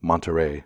MON-tə-RAY, Spanish: [monteˈrej] )[7] is the capital and largest city of the northeastern Mexican state of Nuevo León.
En-us-monterey.ogg.mp3